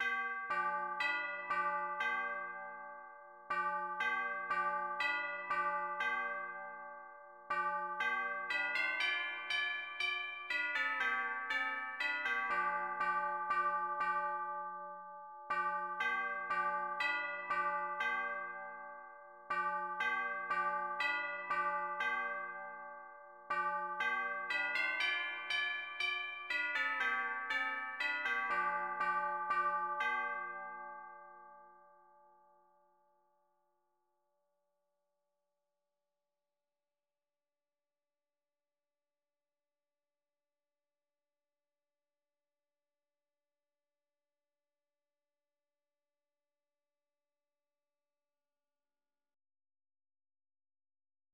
Bécsi harangjáték Műfaj hangszeres komolyzene Szerző Kodály Zoltán A gyűjtés adatai Kiemelt források A Bécsi harangjáték Kodály Zoltán Háry János című daljátékának részlete a II. kaland elejéről.